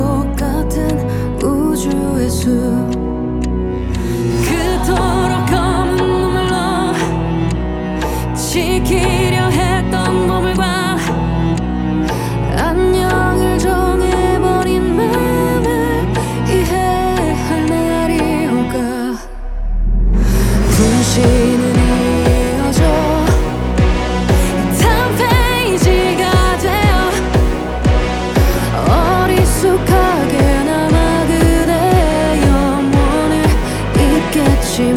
Жанр: Поп / Рок / K-pop